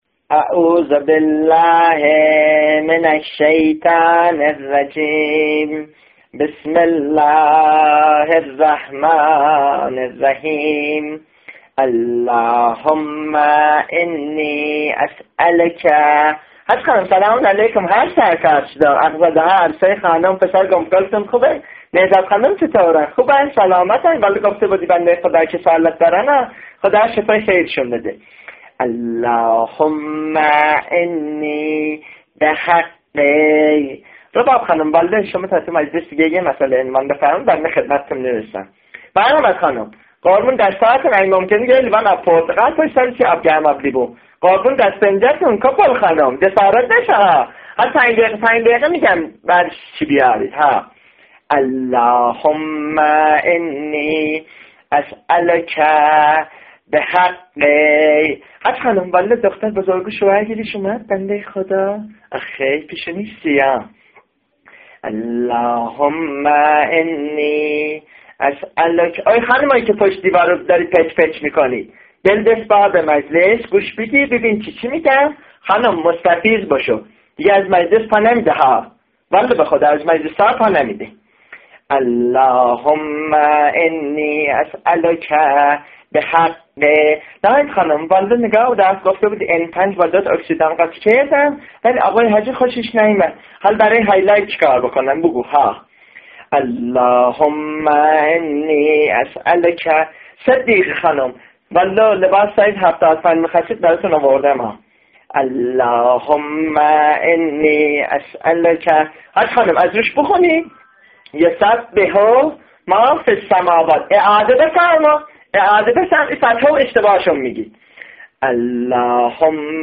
What is this man performing (minus the hillarious interruptions).
qoran.mp3